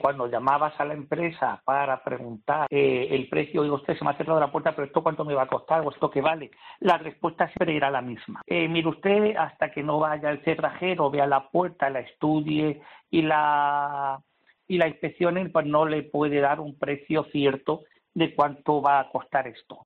El abogado